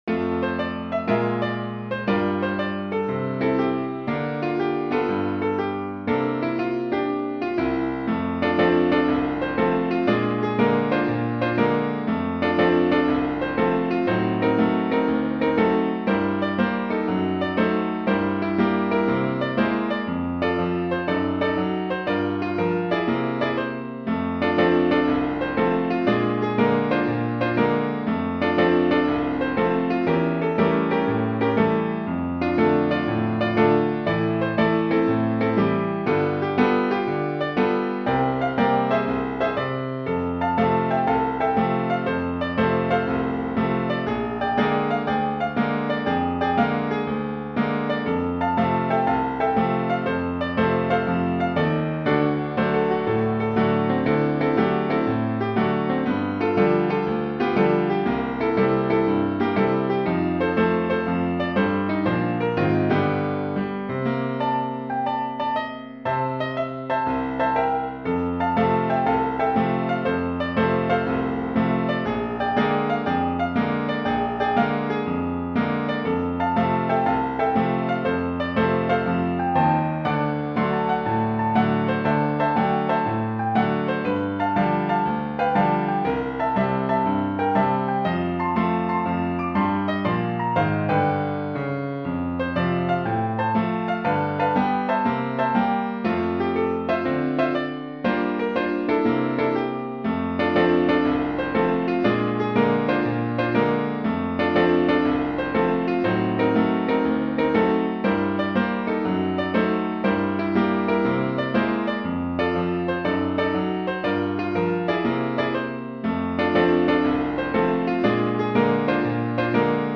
A Blues/Stride song